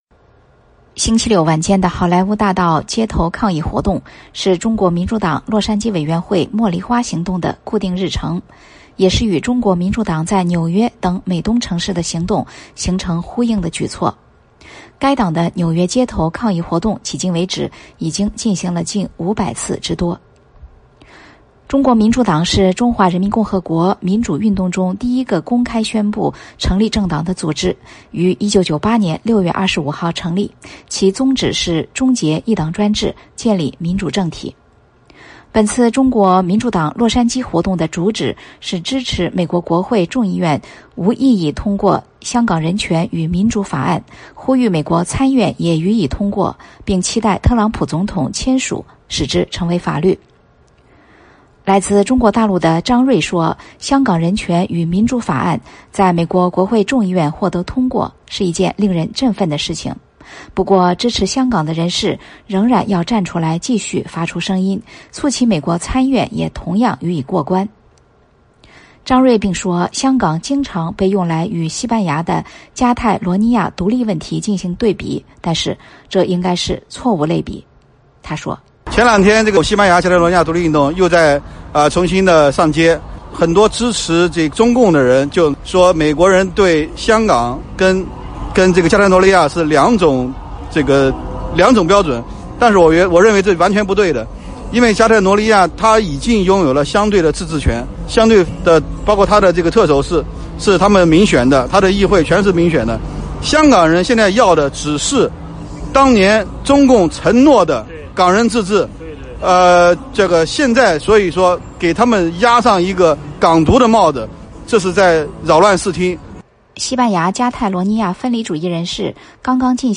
10月19号星期六晚间，中国民主党洛杉矶委员会成员在洛杉矶的好莱坞大道举行“茉莉花行动第六次活动”，支持美国国会众议院15号通过《香港人权与民主法案》，呼吁美国参议院也予以通过，并敦促特朗普总统签署使之成为法律。